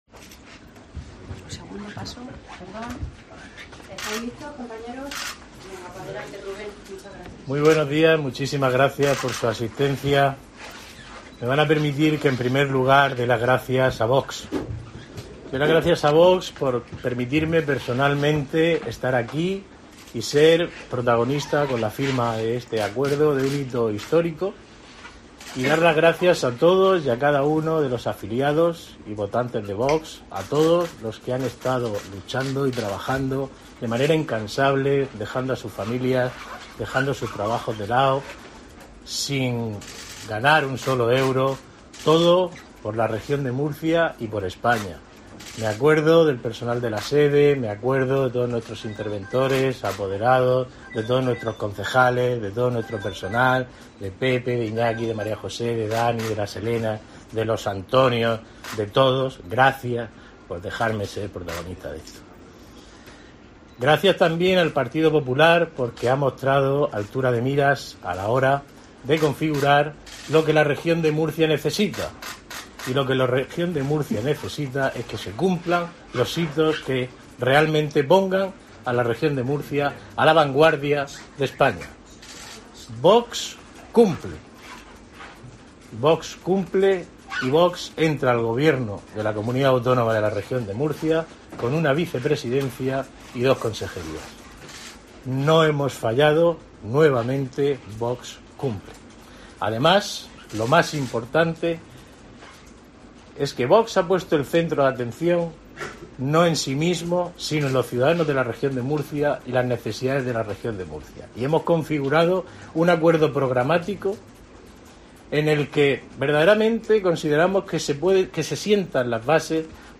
Posteriormente, ambos parlamentarios han intervenido en rueda de prensa.